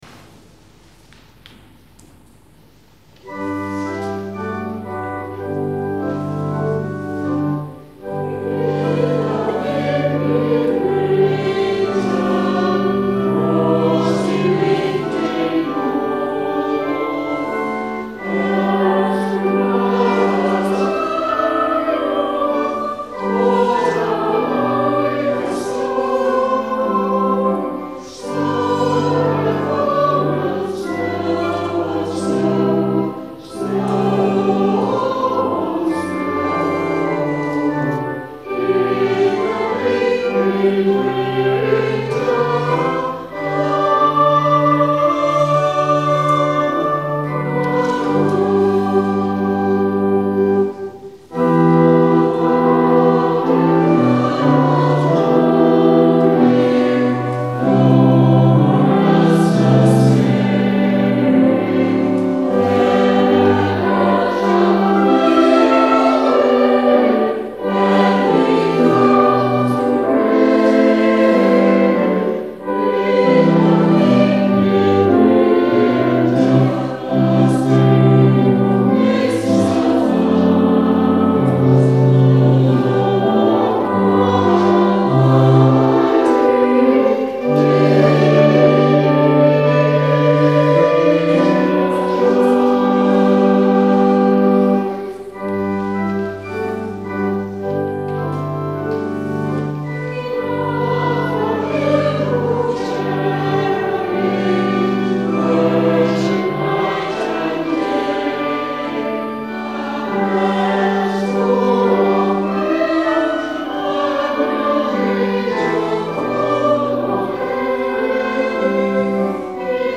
In December 2004 we experimented briefly in recording the choir at Trinity Bowdon, using a tape recorder.
carols.mp3